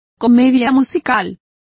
Complete with pronunciation of the translation of musicals.